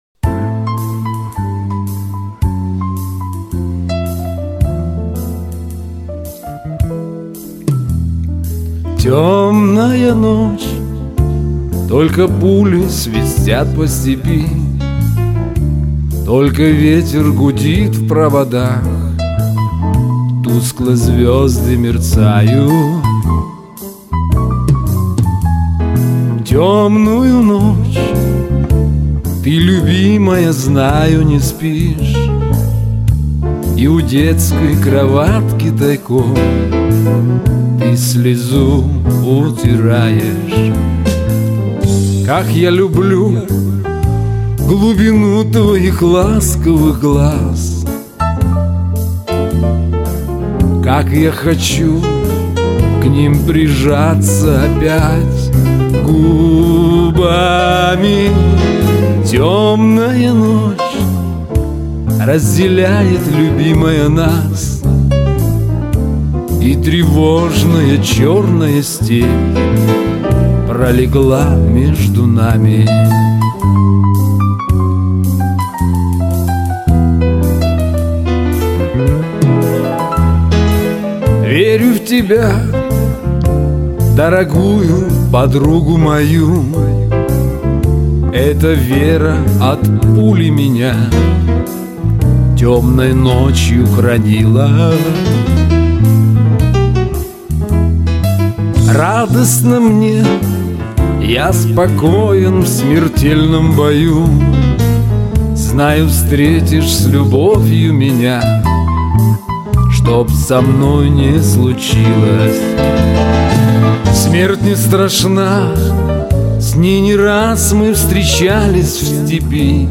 в манере шансона, больше проговаривая слова, нежели пропевая